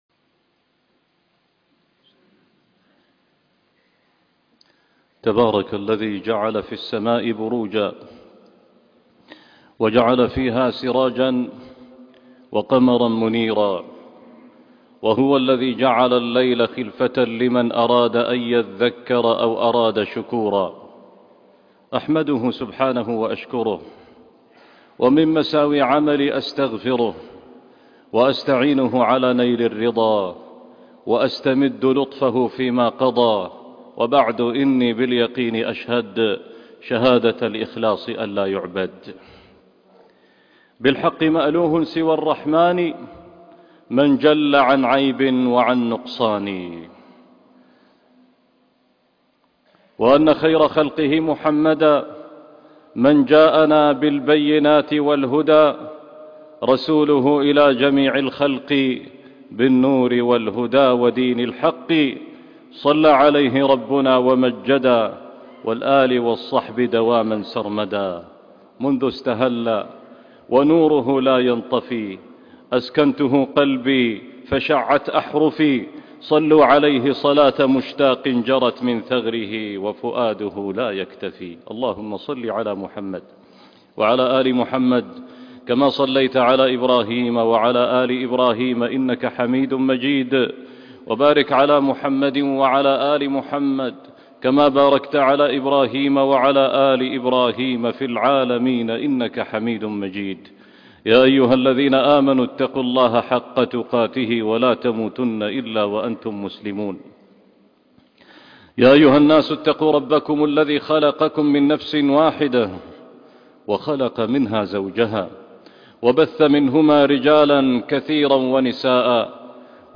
الأخسرون رمضانا - خطبة وصلاة الجمعة